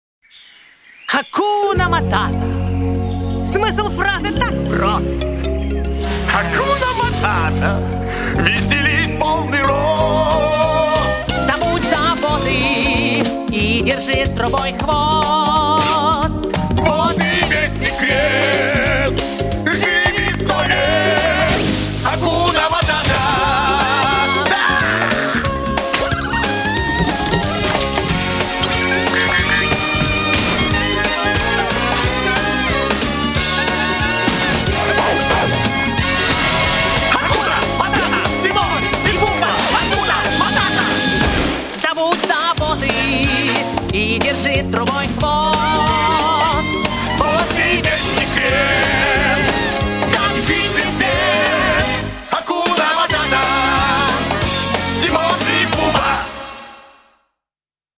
позитивные
добрые
детские
веселье